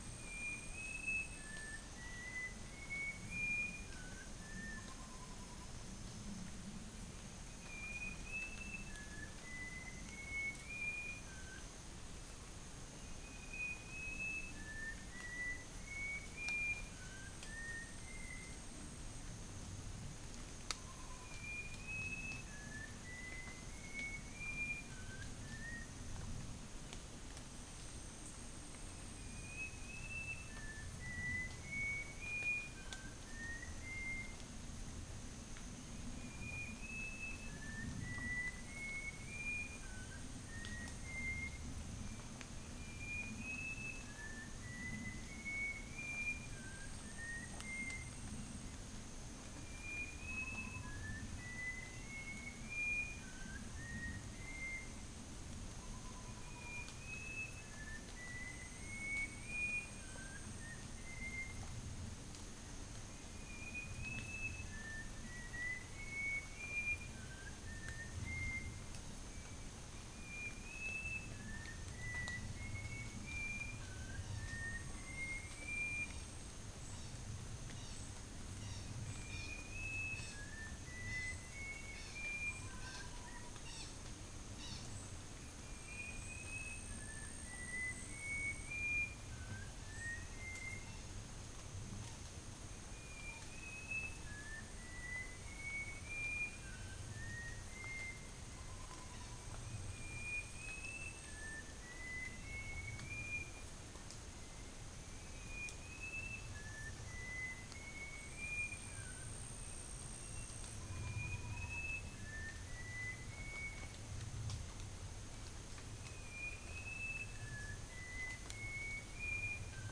Cyanoderma erythropterum
Gracula religiosa
Malacopteron affine
Psittinus cyanurus
Acridotheres javanicus
Arachnothera hypogrammica
Psittacula longicauda
Dicaeum trigonostigma